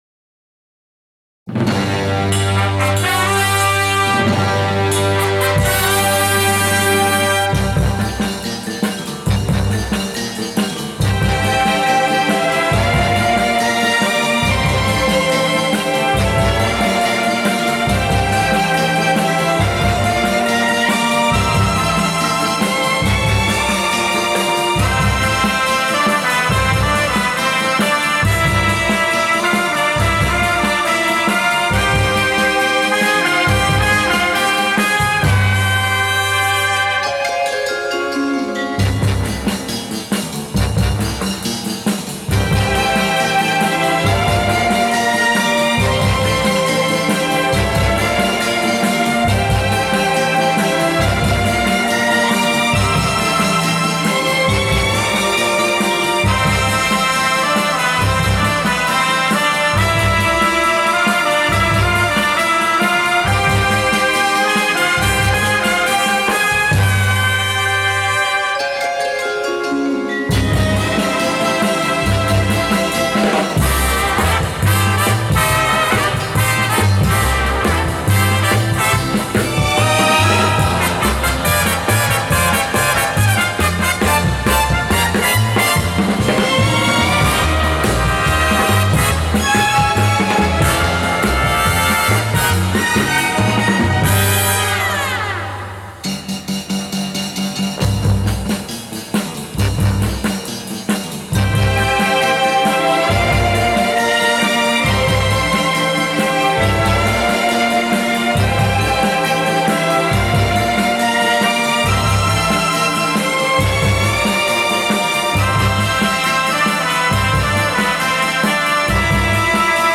perhaps my favorite TV show theme